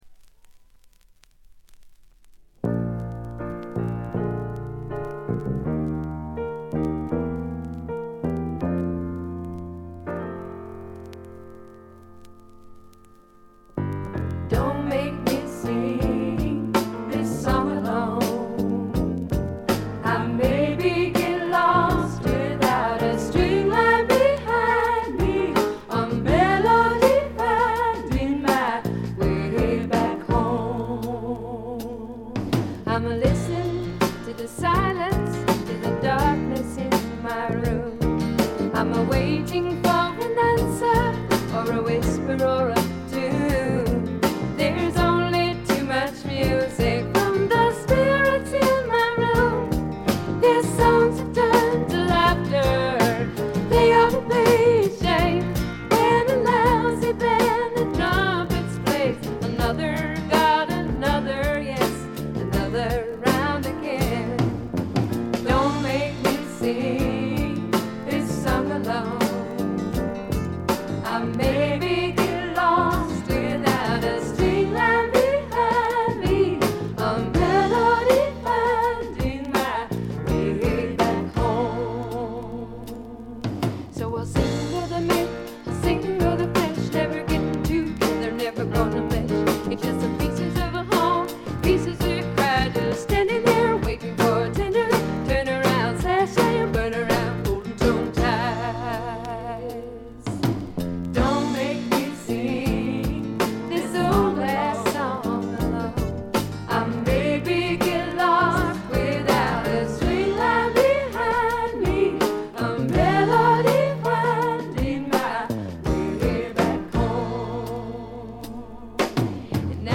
細かなチリプチ程度でまずまず良好に鑑賞できると思います。
試聴曲は現品からの取り込み音源です。
vocals